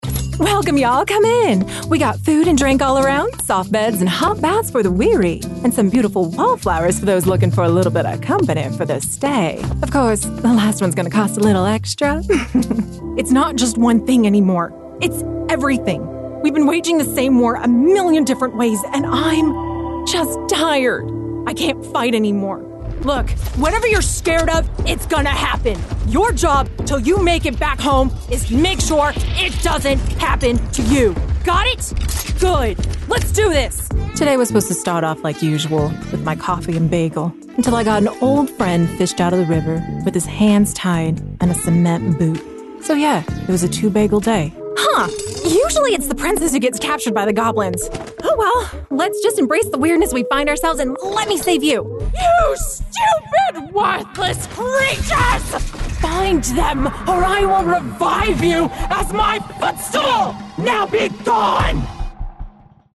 Character/Animation Demo
English(American Neutral), Mid-Atlantic, Irish, Southern American General